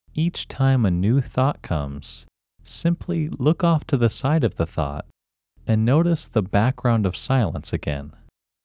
WHOLENESS English Male 4
WHOLENESS-English-Male-4.mp3